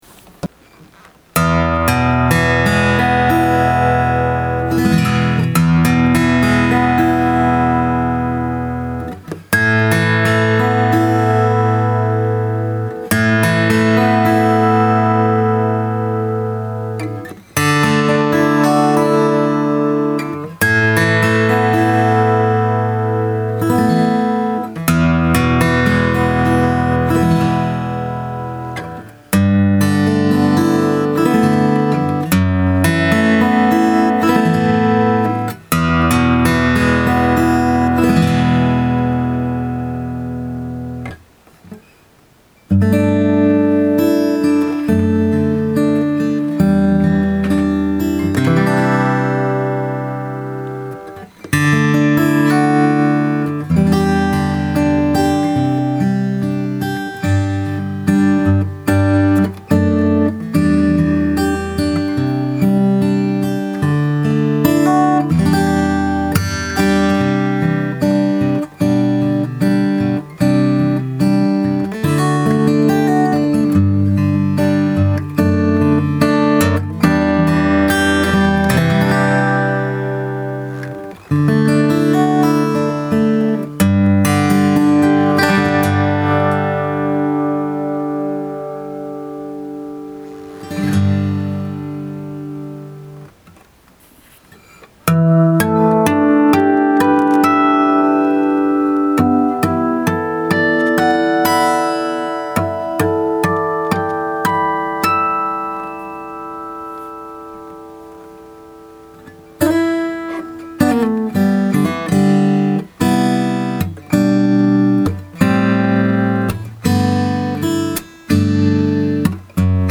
ナット，サドルは無漂白ボーンで，少し飴色のタイプですね。
オリンパスのメモリーレコーダーで録ってみます。
イヤホンで聞きながらいい音と感じる場所と向きにマイクを合わせて弾きますよ。 思いつくまま，ギターを握ったまま，適当に気持ちがいい音がするなーという勝手な気持ちでコードを弾いてみます。